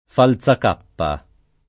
Falzacappa [ fal Z ak # ppa ] cogn.